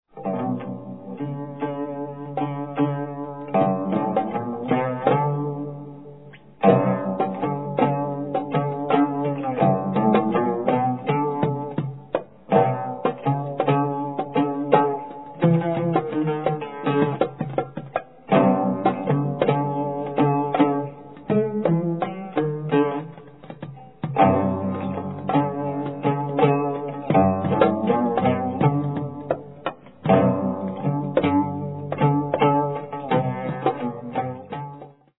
traditional Near Eastern style
Ensemble, with Oud